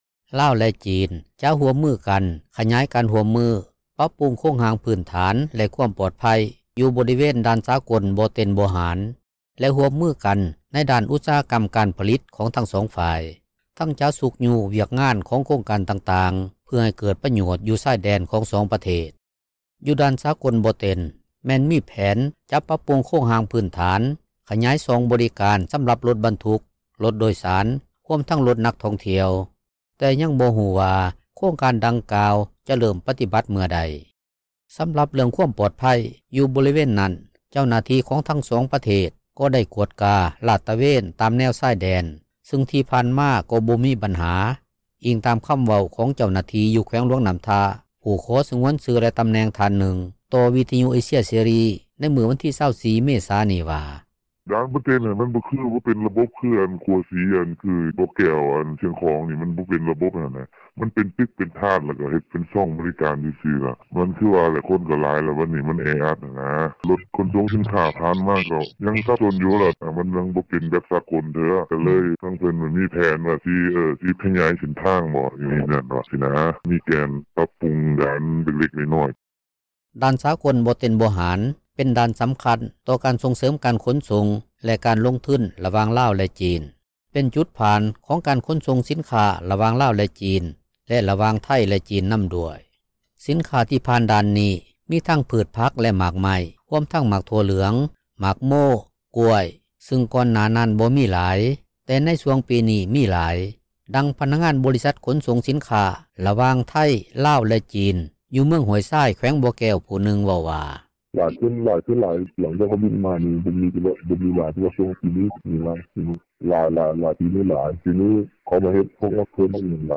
ດັ່ງພະນັກງານບໍລິສັດຂົນສົ່ງສິນຄ້າ ລະຫວ່າງໄທ ລາວ ແລະຈີນ ຢູ່ເມືອງຫ້ວຍຊາຍ ແຂວງບໍ່ແກ້ວຜູ້ນຶ່ງເວົ້າວ່າ:
ດັ່ງປະຊາຊົນ ຢູ່ບໍລິເວນດ່ານບໍ່ເຕັນ ຜູ້ນຶ່ງເວົ້າວ່າ: